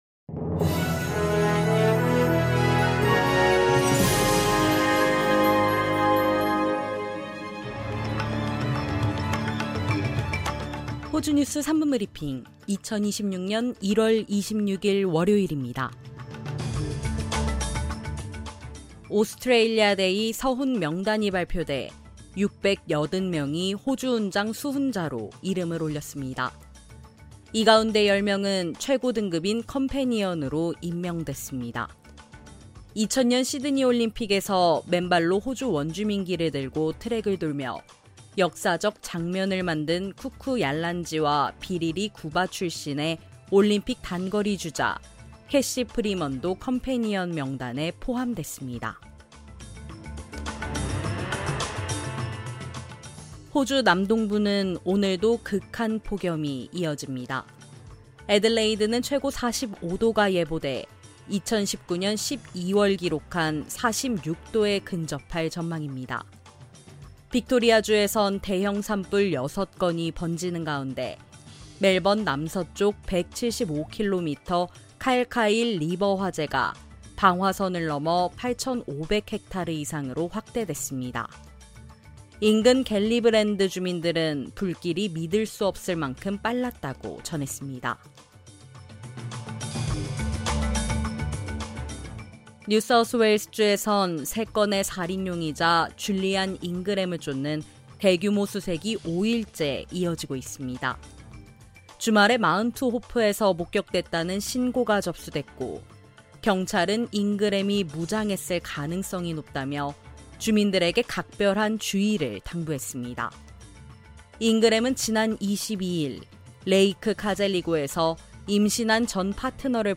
호주 뉴스 3분 브리핑: 2026년 1월 26일 월요일